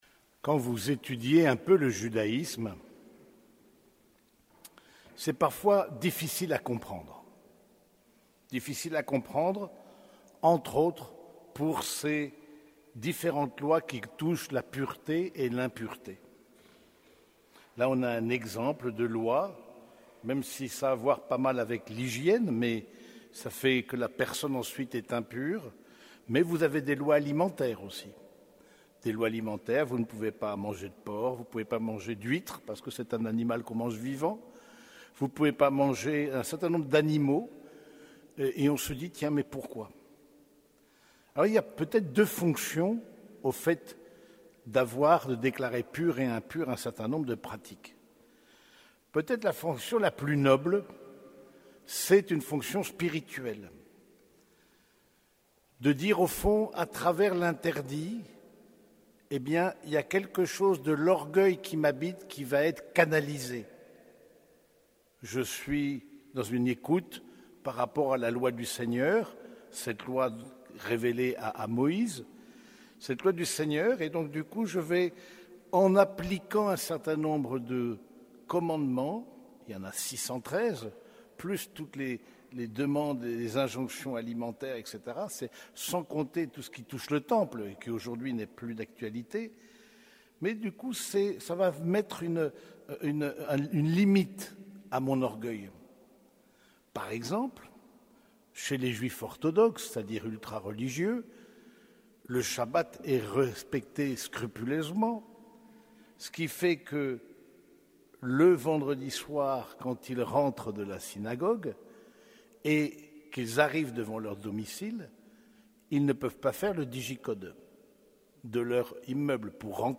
Homélie du 22e dimanche du Temps Ordinaire